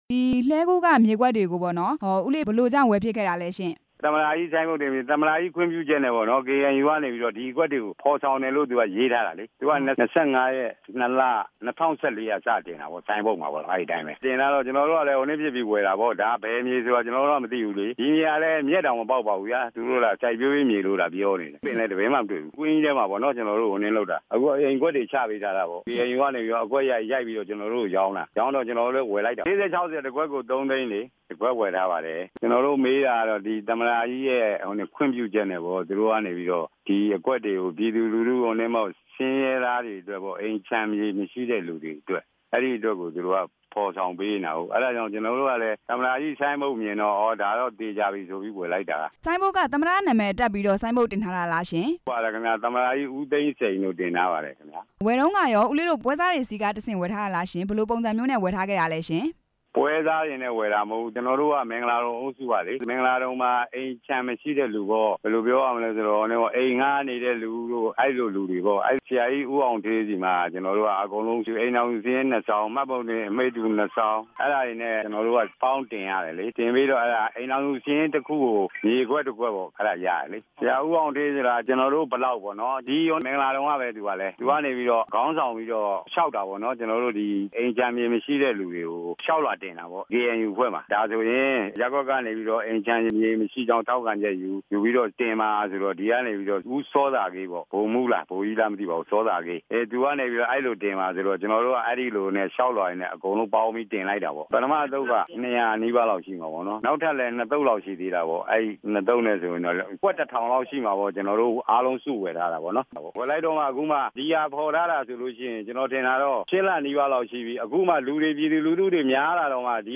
KNU ရောင်းချမြေပေါ်က ဖယ်ရှား ခိုင်းတဲ့အကြောင်း မေးမြန်းချက်